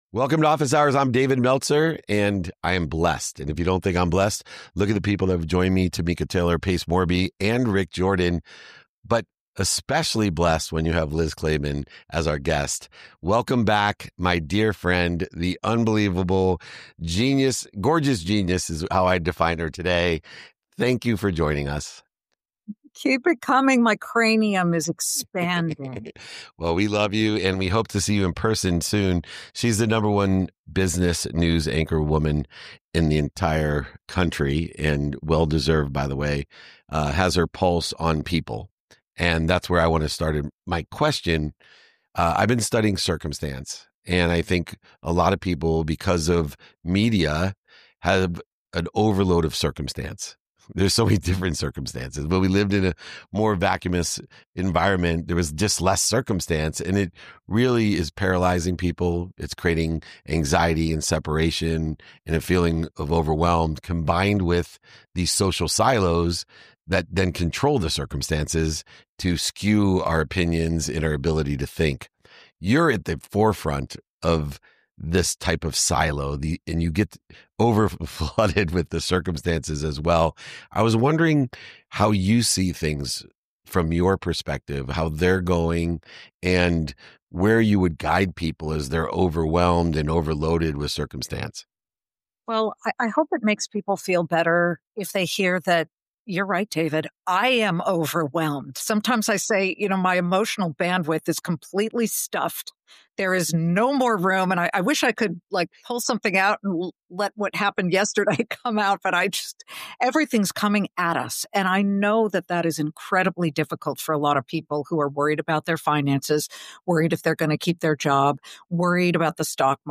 In today’s episode, I sit down with Liz Claman, the number one business news anchorwoman in America, for a conversation on staying grounded in an overwhelming world.